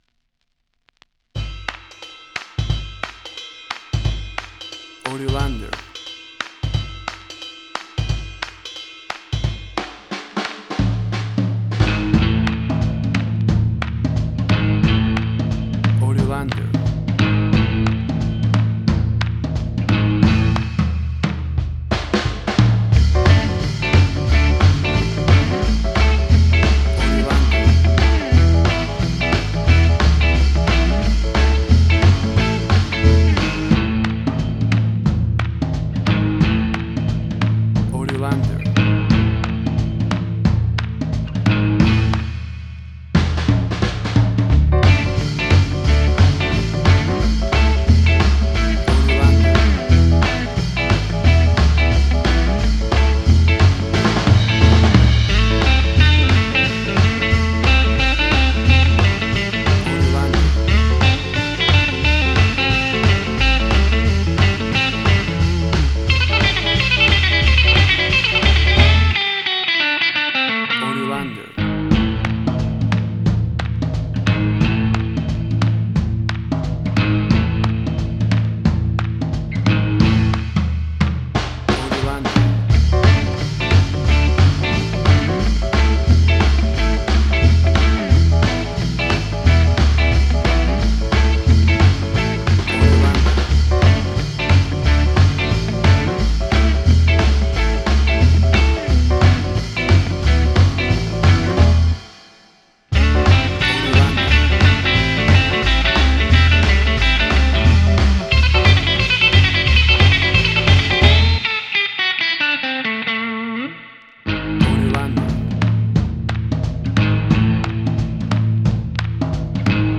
1950s Rock N Roll
WAV Sample Rate: 16-Bit stereo, 44.1 kHz
Tempo (BPM): 89